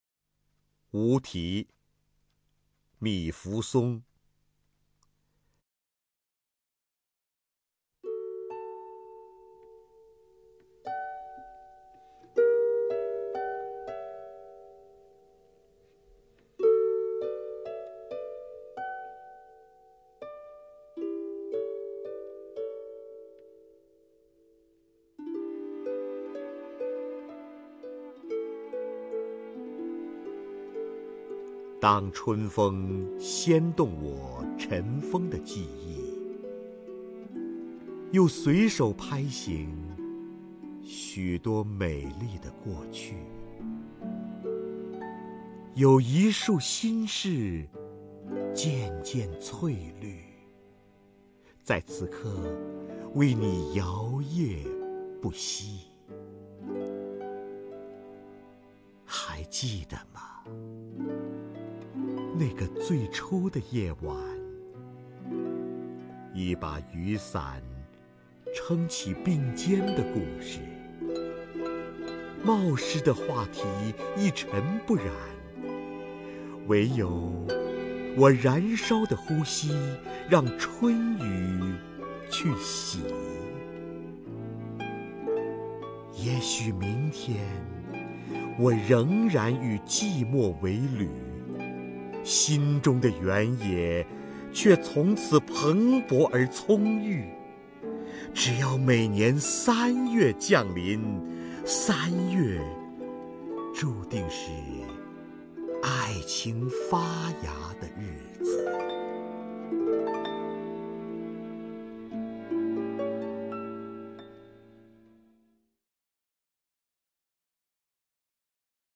瞿弦和朗诵：《无题》(糜福松)
名家朗诵欣赏 瞿弦和 目录